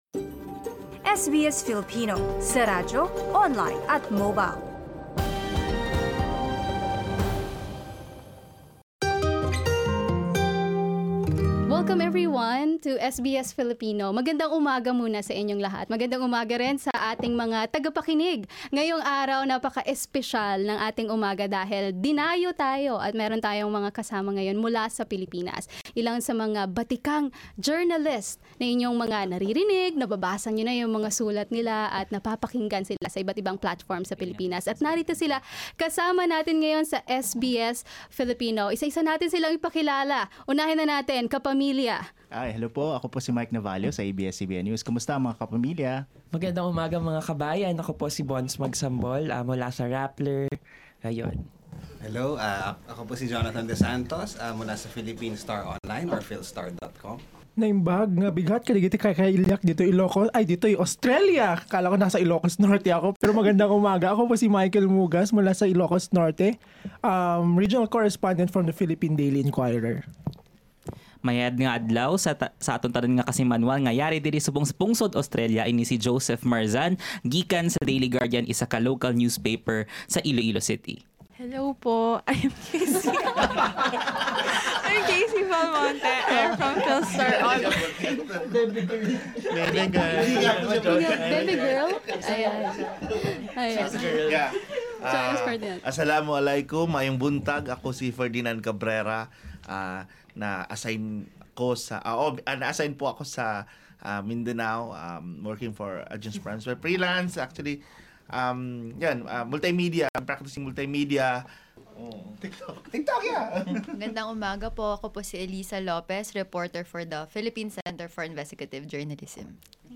Pakinggan ang mga pananaw at karanasan sa mundo ng pamamahayag sa Pilipinas ng mga Filipino journalist na bumisita sa Australia at alamin kung paanong makakatulong ang paraan ng pamamahayag ng SBS sa paghahatid ng impormasyon at balita sa mga komunidad.